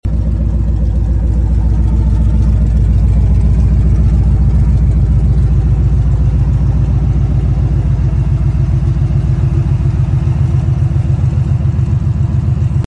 Intensified bald eagle sounds 🦅 sound effects free download